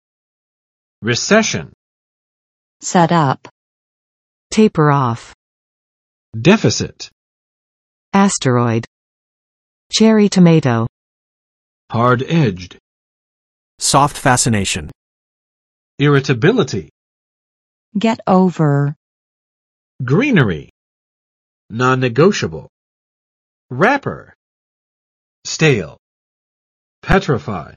[rɪˋsɛʃən] n.（经济的）衰退；衰退期